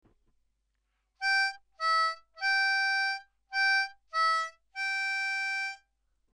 Tunes to Play
Here’s the whole tune.